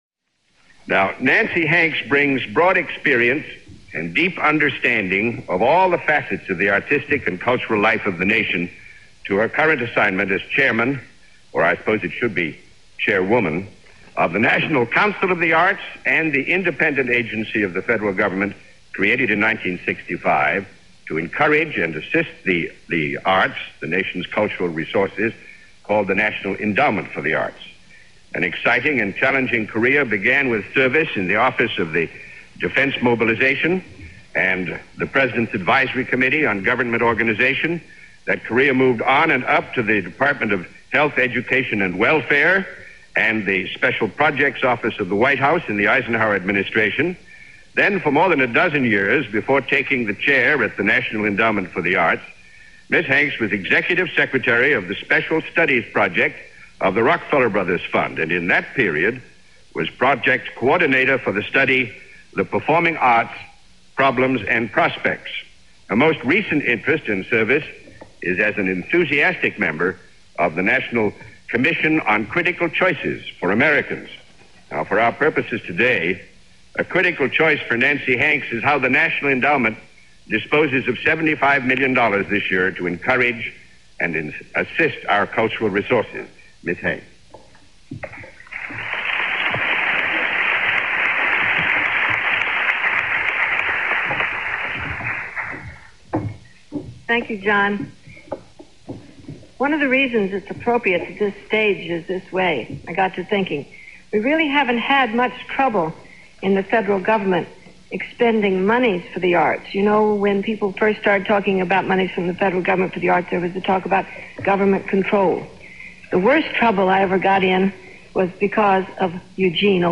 Although the condescending tone leaves nothing to the imagination on either Hanks or Boorstin’s parts.